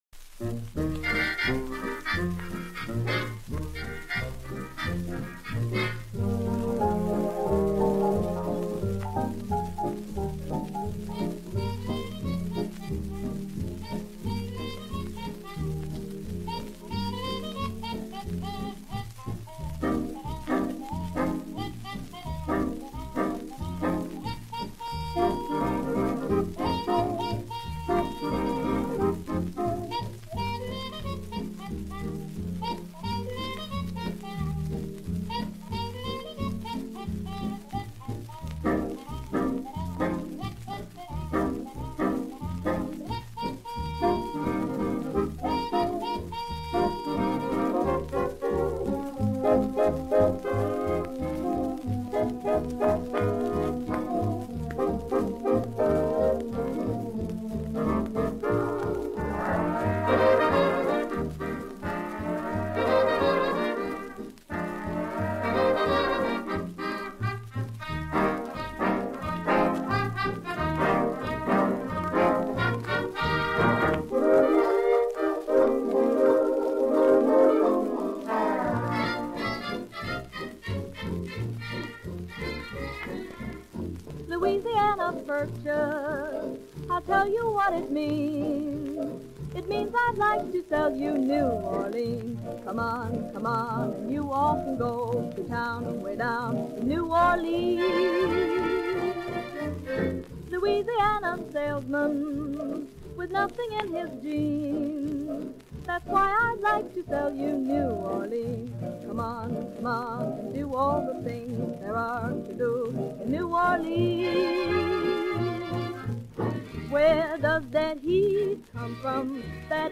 radio-music.2b69f921527f86be239e.mp3